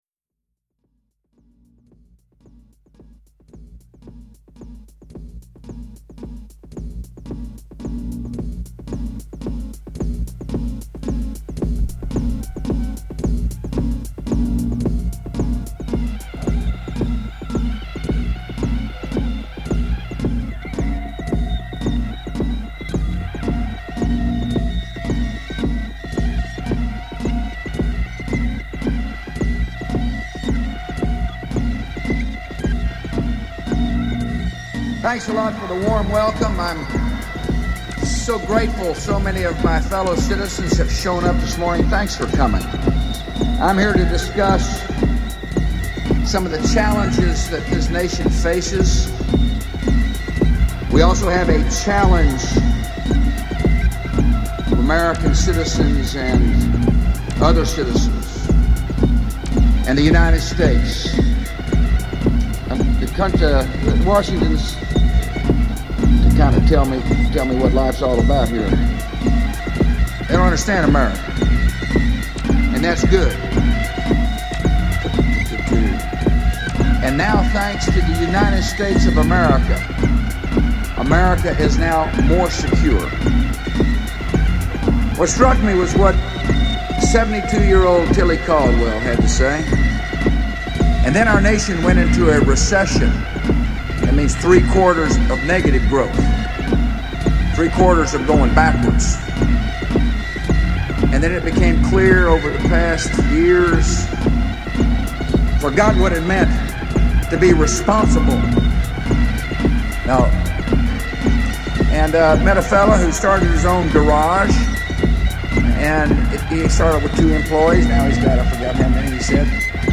heres three pieces of cut up bush gibbering put to music.